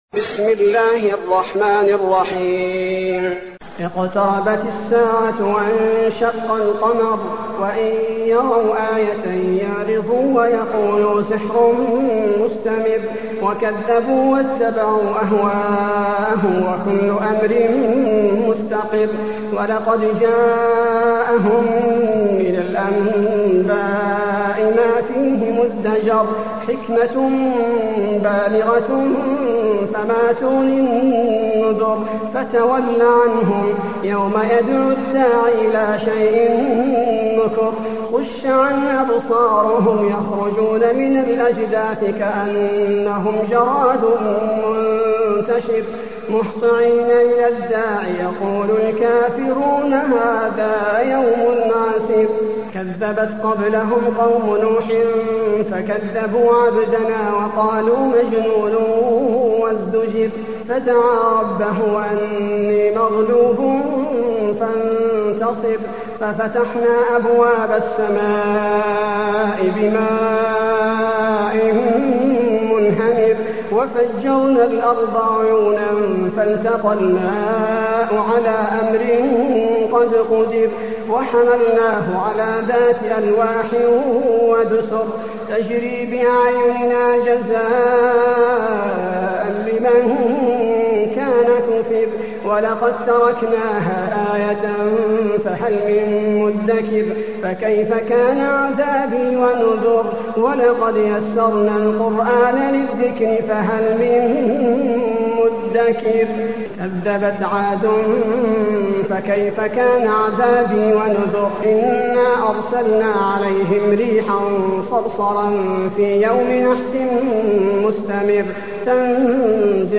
المكان: المسجد النبوي القمر